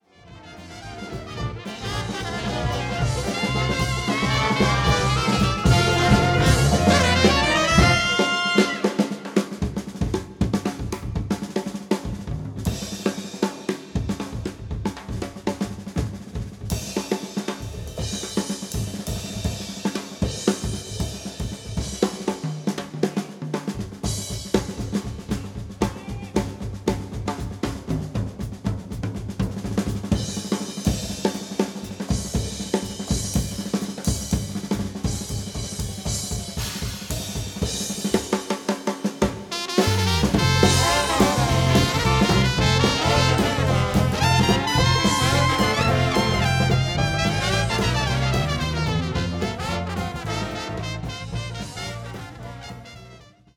drums
When the saints (reprise) with drum solo (22.02.2011 / Basingstoke)